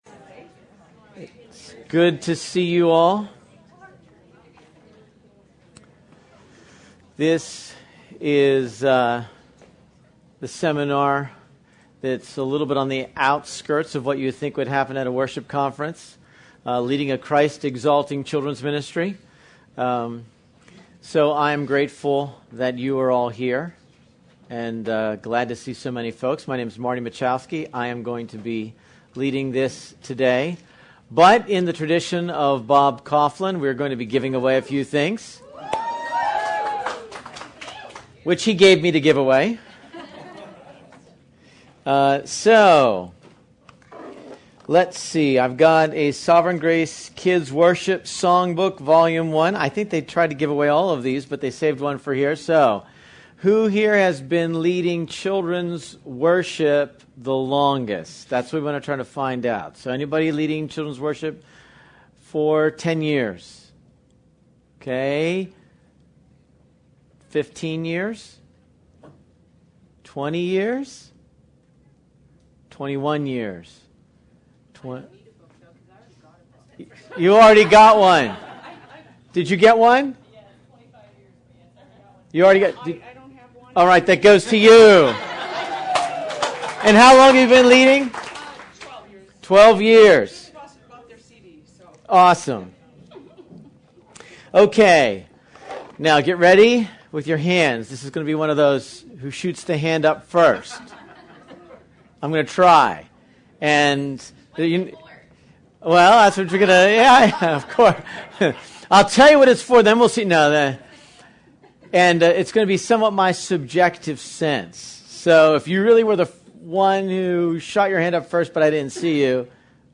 Format(s): Conference Messages , Seminars